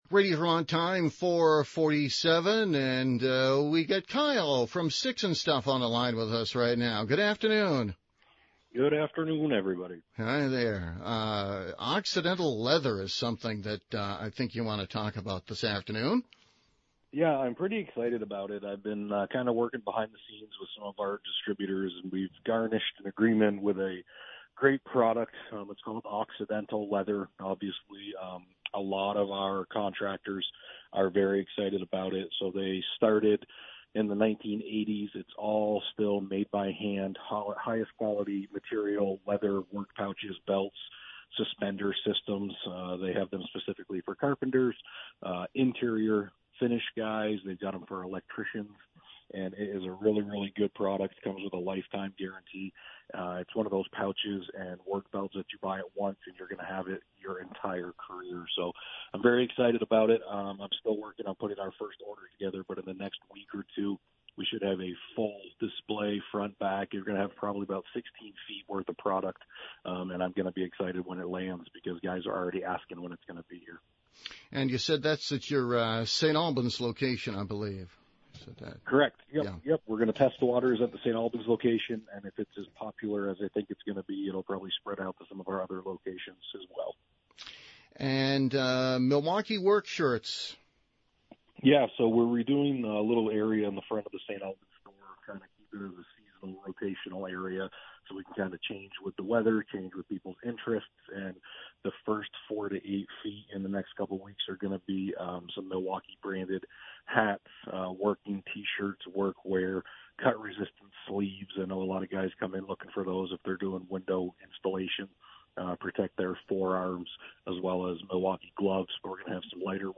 live on WDEV and Radio Vermont Group.